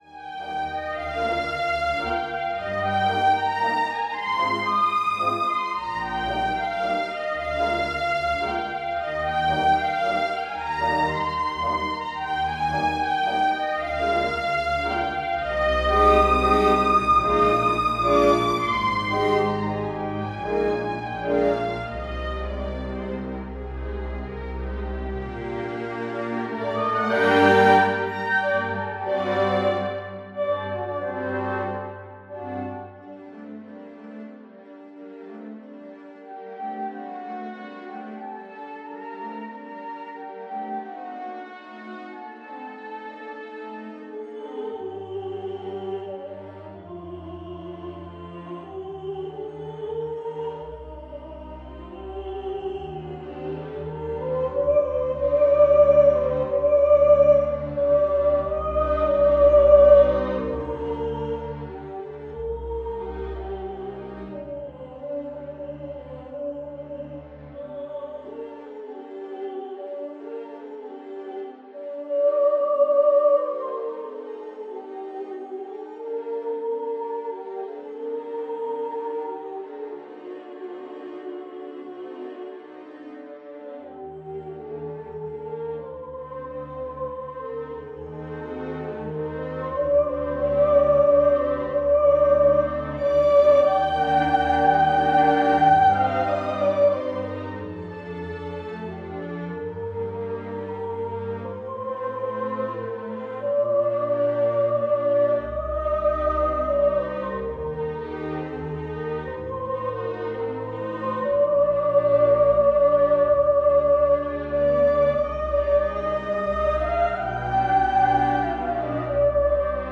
Emma’s aria
A demo created with Vienna Instruments Sampled Libraries
emmas-waltz.mp3